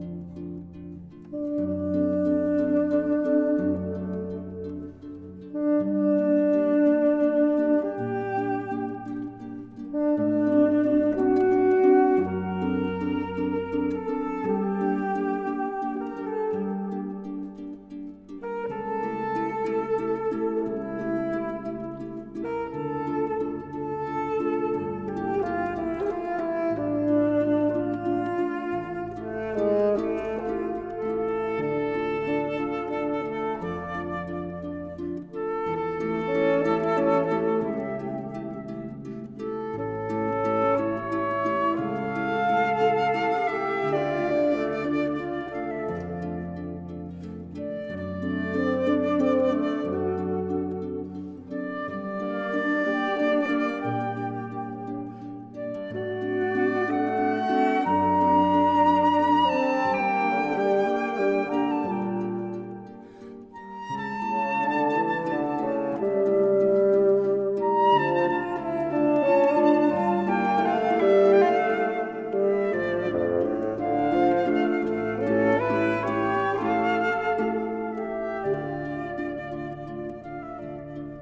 室內樂作品